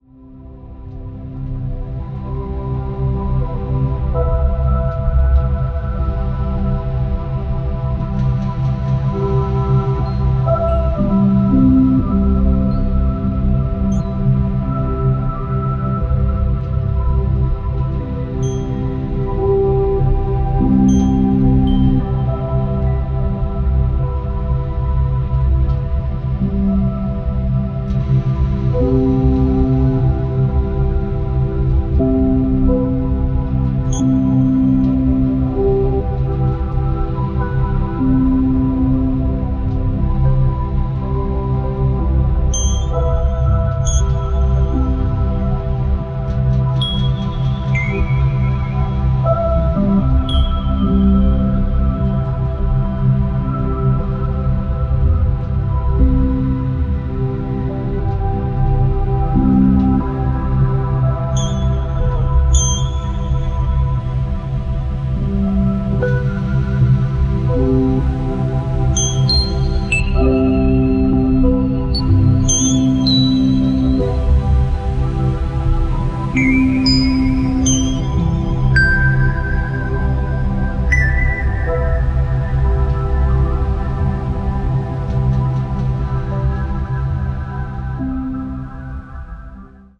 空間を引き立て、聴き手に沈黙・静寂を求める純正のアンビエント音楽集です。